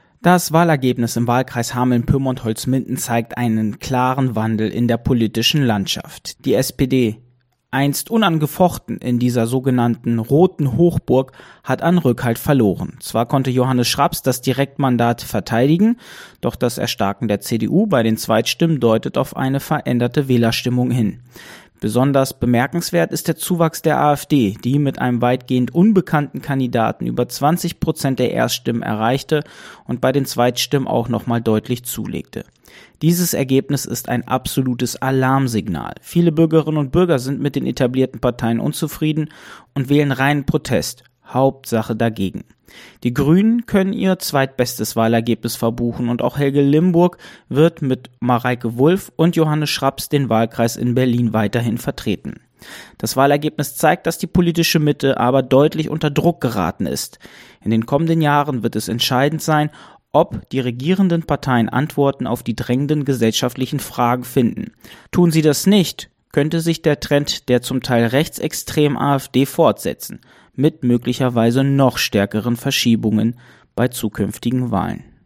Kommentar zur Bundestagswahl
Aktuelle Lokalbeiträge Kommentar zur Bundestagswahl Play Episode Pause Episode Mute/Unmute Episode Rewind 10 Seconds 1x Fast Forward 30 seconds 00:00 / 1:15 Download file | Play in new window | Duration: 1:15